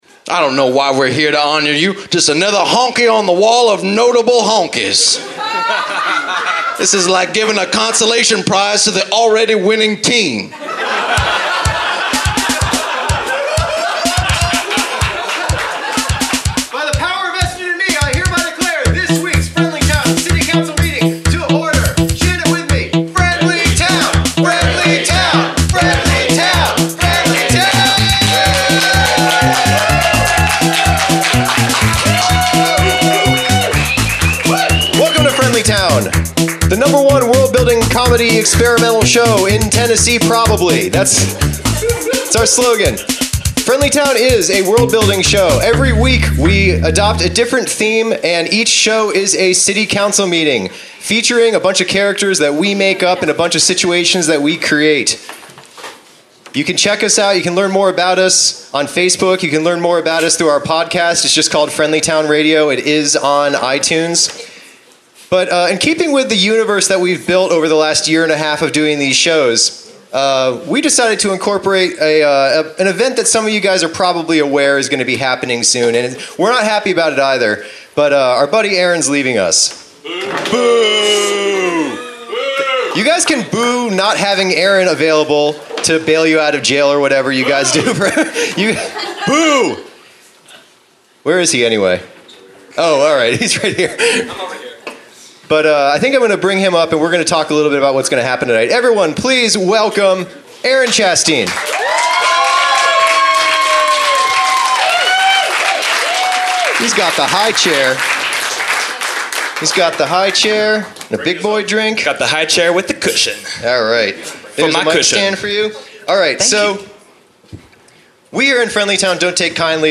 Recorded Live at the Pilot Light January 29, 2017, Knoxville TN Share this: Share on X (Opens in new window) X Share on Facebook (Opens in new window) Facebook Share on Pinterest (Opens in new window) Pinterest Like Loading...